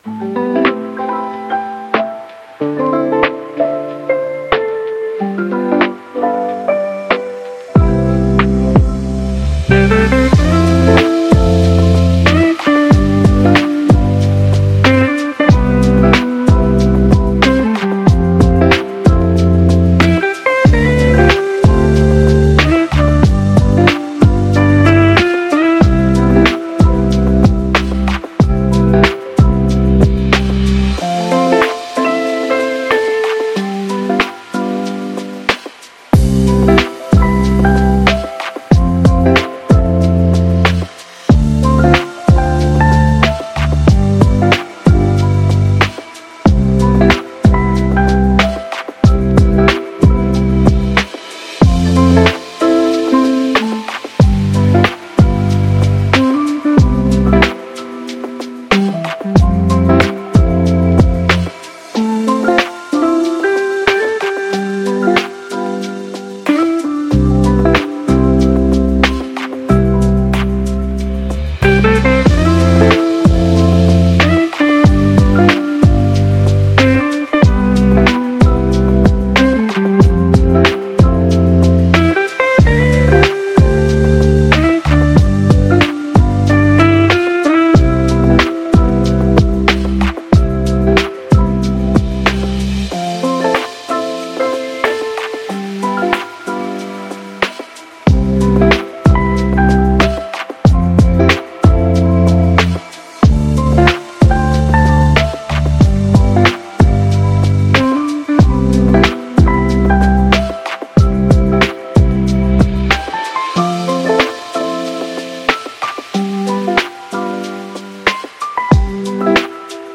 Positive Lofi